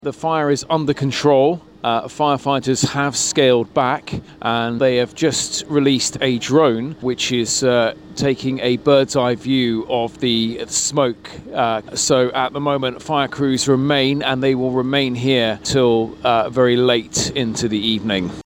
at a Richmond Road fire in Kingston